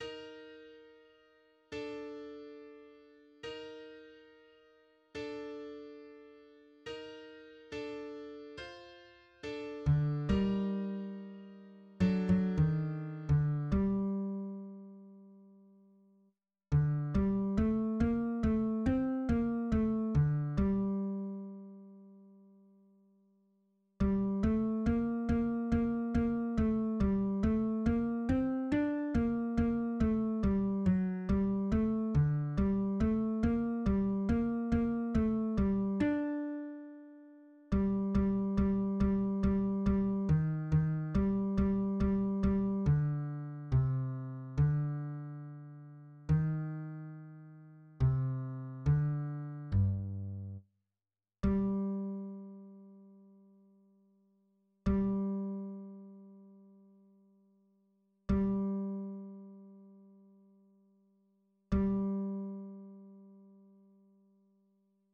} >> \new Lyrics \lyricsto "three" {\set fontSize = #-2 } \new Staff \with {midiInstrument = #"acoustic bass" instrumentName = "B" shortInstrumentName = "B"} << \set Staff.midiMinimumVolume = #14.7 \set Staff.midiMaximumVolume = #15.9 \set Score.currentBarNumber = #1 \bar "" \tempo 4=70 \time 4/4 \key bes \major \clef bass \new Voice = "four"{ \voiceOne r1 r r2 r4 r8 d g2 \tuplet 3/2 {fis8 fis ees~} ees d g2. r8 d g a bes a c' bes a d g1 g8 a bes bes bes bes a g a bes c' d' bes bes a g fis g a d g a bes g bes bes a g d'2 g8 g g g g g ees ees g g g g d4 c d2 ees c4 d g, r4 g1 g g g } >> \new Lyrics \lyricsto "four" {\set fontSize = #-2 Es bret, bri- der lekh- es brent oy und- der or- em shtet- tl ne- bekh brent bey- ze vin- tn mit yir- goz- en ray- sn brek- hn un tse- blo- zn shtar- ker nokh di vil- de fla- men alts ar- um shoyn brent un ir shteyt un kukt a- zoy zikh mit far- leyg- te he- nt oo oo oo oo oo s'brent s'brent s'brent s'brent } >> \midi{}